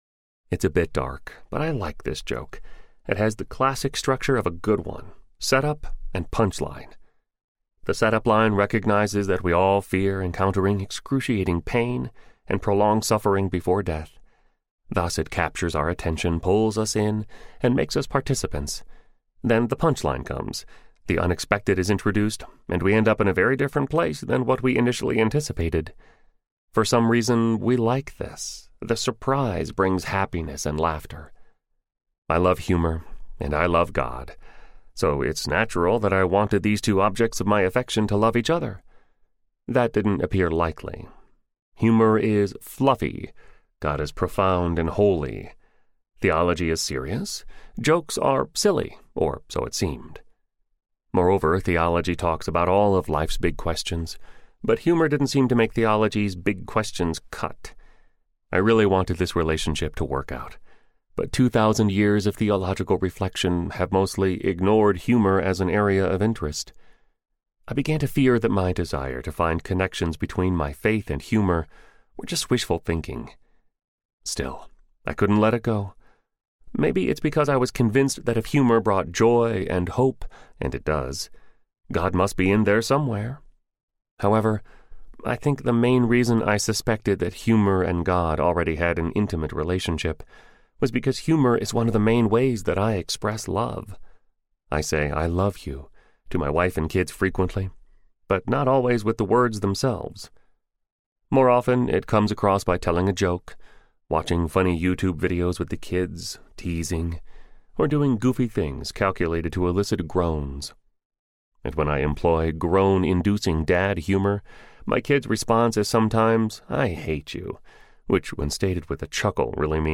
What’s So Funny About God? Audiobook
Narrator
6.4 Hrs. – Unabridged